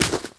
lazer_step_02.wav